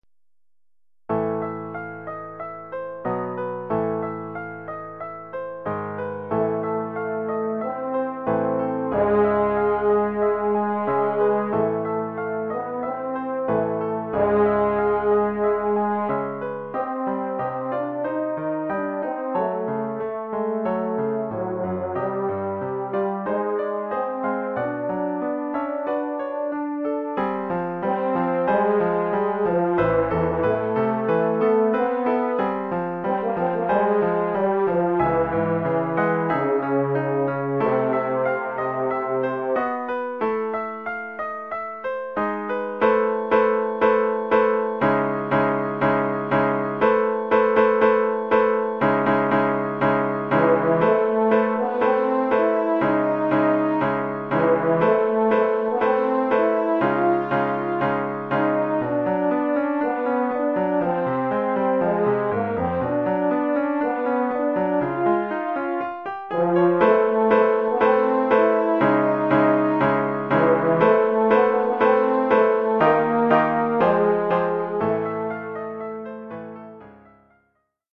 Oeuvre pour cor d'harmonie
(fa ou mib) et piano.